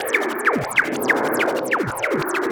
RI_ArpegiFex_95-03.wav